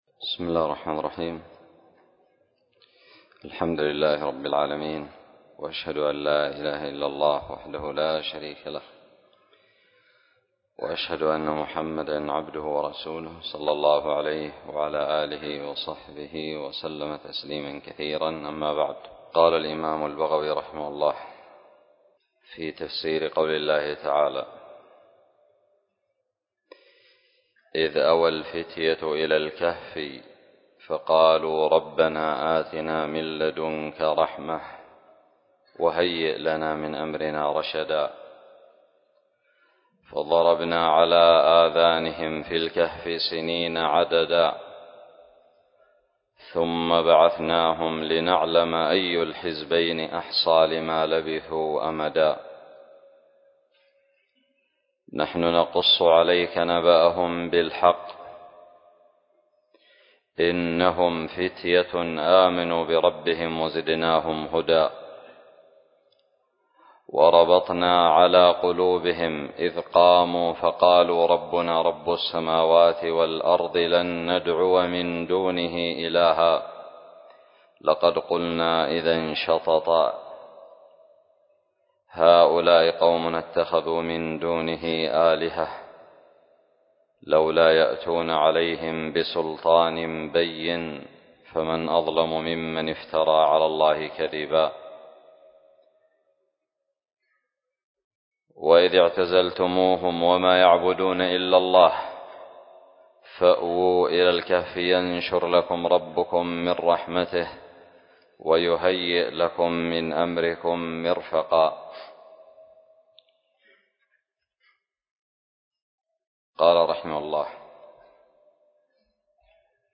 16 الدرس الرابع من تفسير سورة الكهف من تفسير البغوي من آية 11 إلى آية
لقيت بدار الحديث السلفية للعلوم الشرعية بالضالع